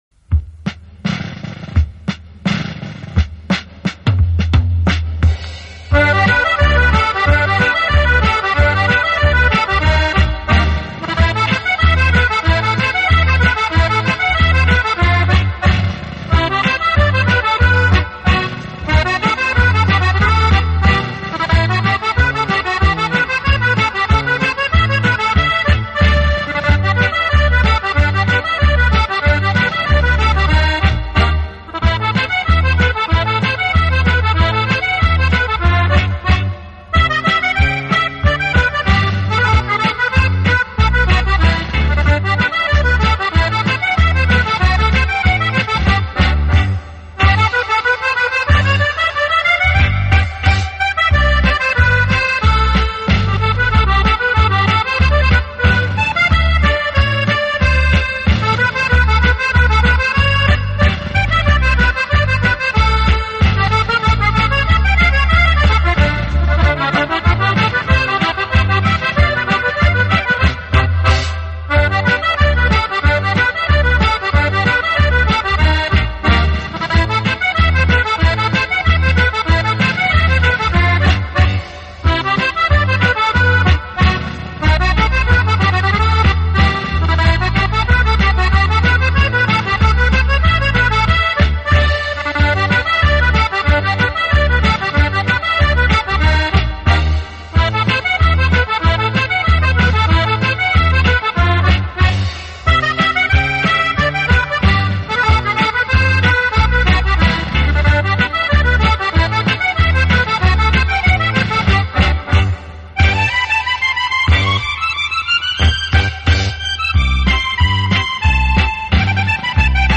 优美的手风琴曲
音乐类型: Pop, Instrumental Accordeon